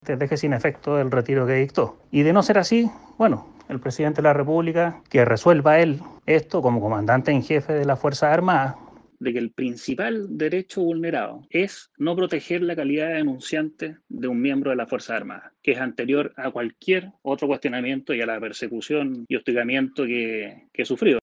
En conversación con Radio Bío Bío, el militar en retiro afirmó que el Ejército no protegió su calidad de denunciante, vulnerando uno de sus derechos principales.